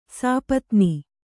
♪ sāpatni